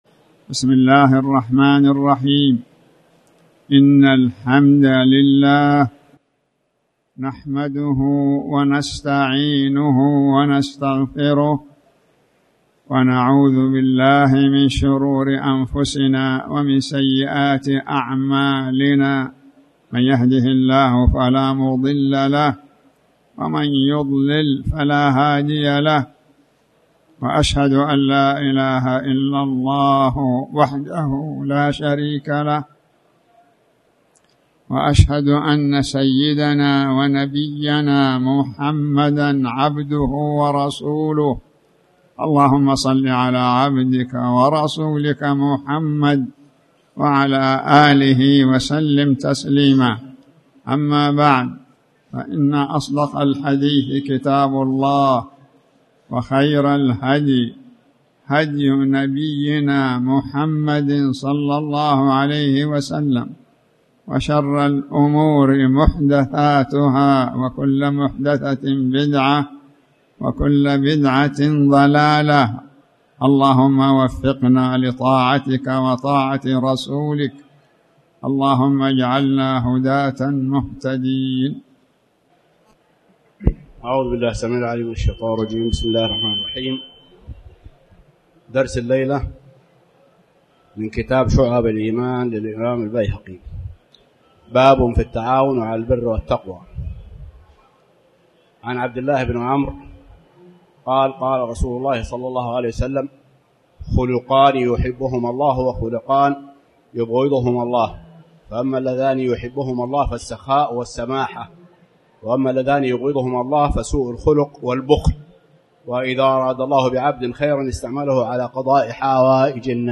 تاريخ النشر ١٣ محرم ١٤٣٩ هـ المكان: المسجد الحرام الشيخ